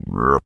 pgs/Assets/Audio/Animal_Impersonations/frog_deep_croak_04.wav
frog_deep_croak_04.wav